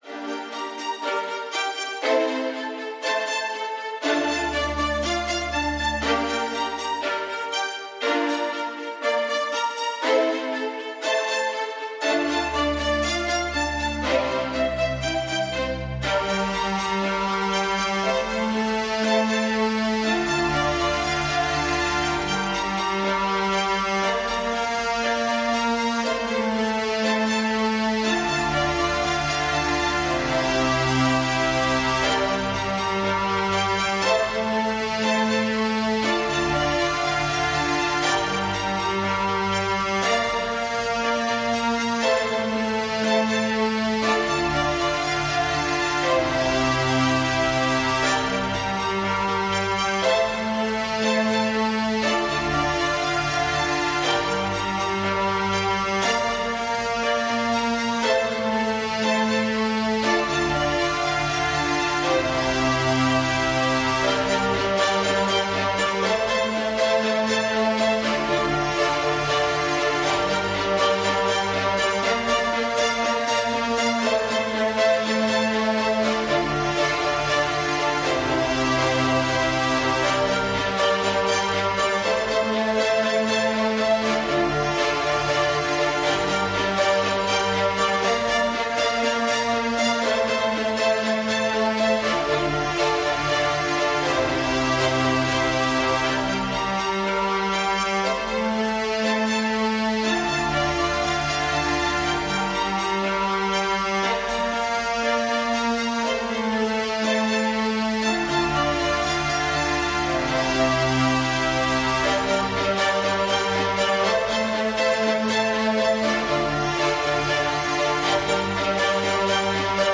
une musique libre de droit épique